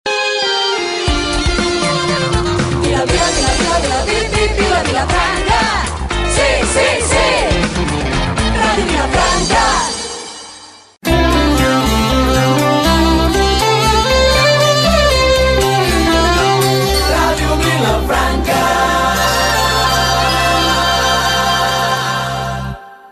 Dos indicatius de l'emissora